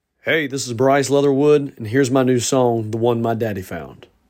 LINER-Bryce-Leatherwood-The-One-My-Daddy-Found.mp3